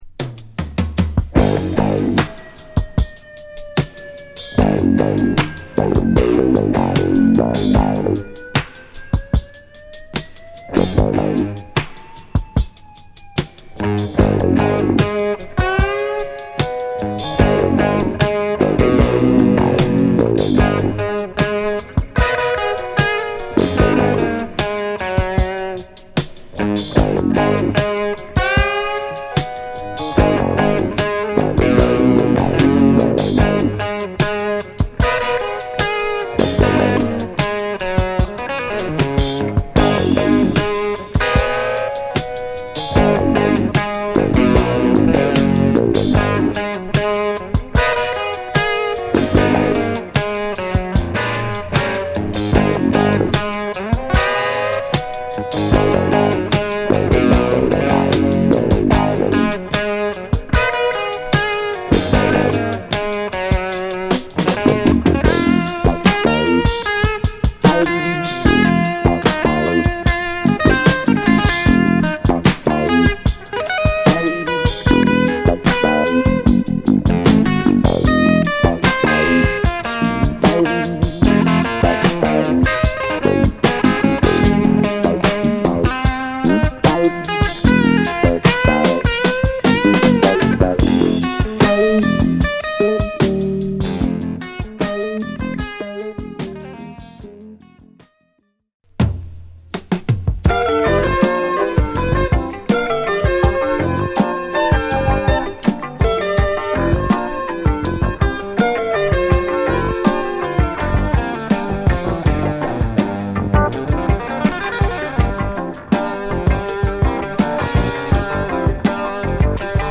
ファンキーギター炸裂！